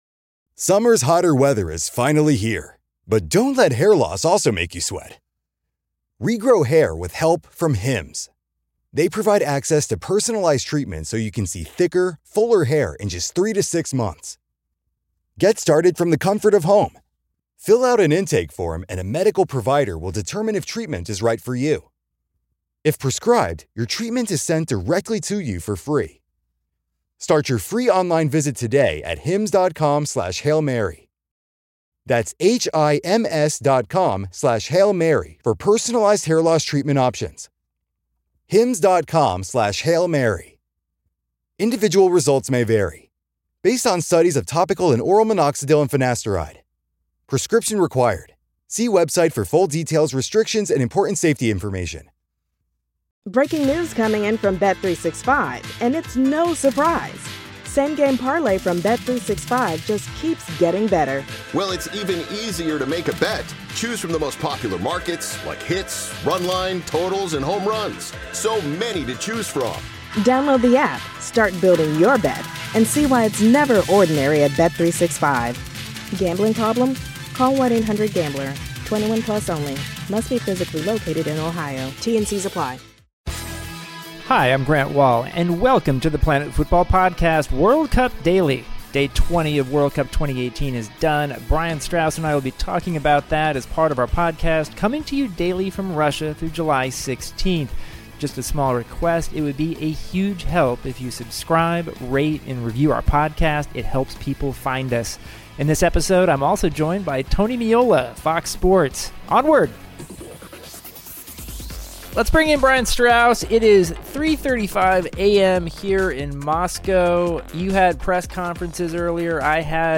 Then Grant interviews Tony Meola of Fox Sports about his thoughts on the tournament.